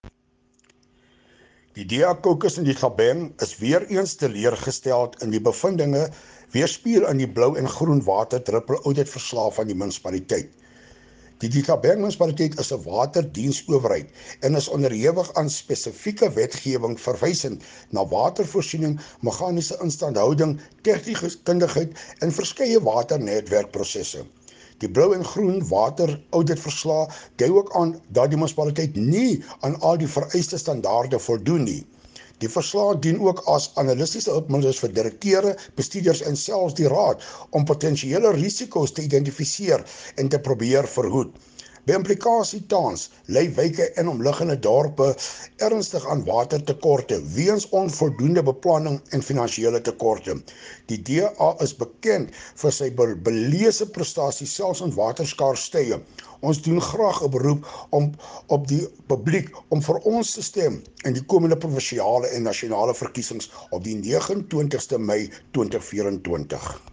Afrikaans soundbites by Cllr Hilton Maasdorp and Sesotho by Karabo Khakhau MP.